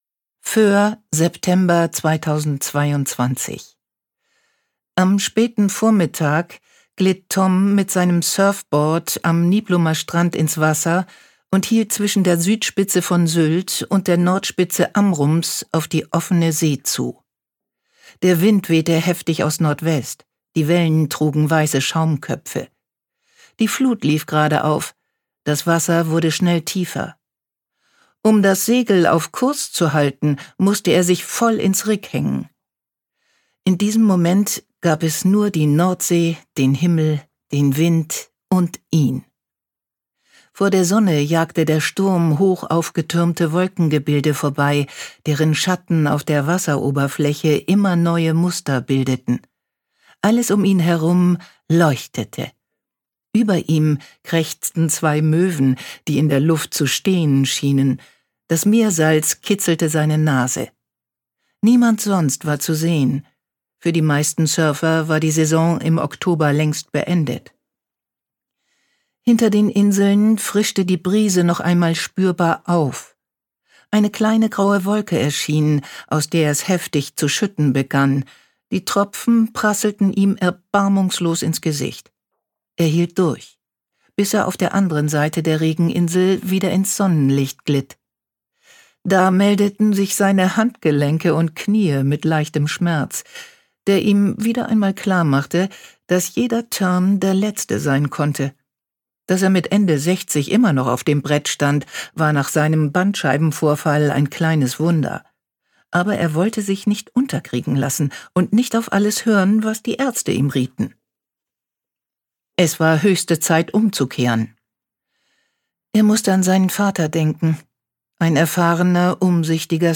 Sabine Kaacks dunkle, prägnante Stimme hat jede:r im Ohr, die oder der jemals Diese Drombuschs gesehen hat. Charmant, lebendig und frisch macht sie dieses Hörbuch zu einem Erlebnis.
Das Salz in der Luft Gelesen von: Sabine Kaack